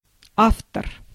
Ääntäminen
Synonyymit творец сочинитель создатель Ääntäminen : IPA: [ˈɑf.tər] Haettu sana löytyi näillä lähdekielillä: venäjä Käännös Ääninäyte Substantiivit 1. author US 2. writer RP US UK 3. auteur Translitterointi: avtor.